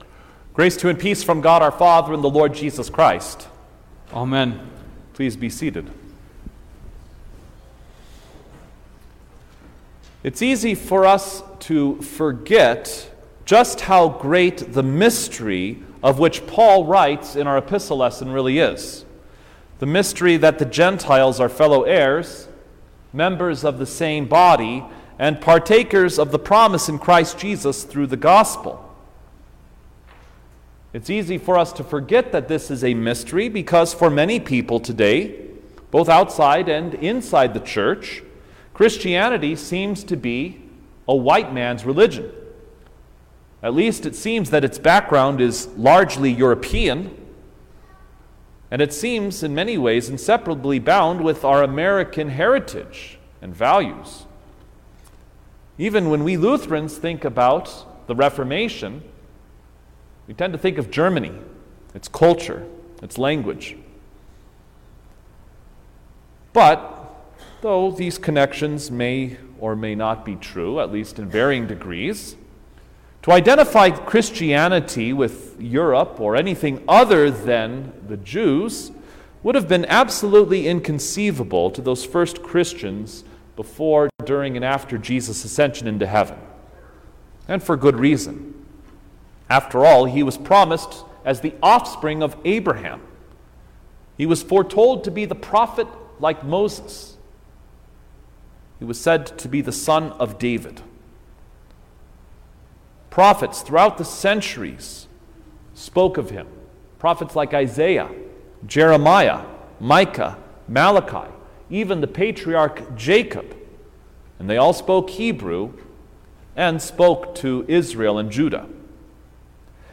January-6_2023_The-Epiphany-of-Our-Lord_Sermon-Stereo.mp3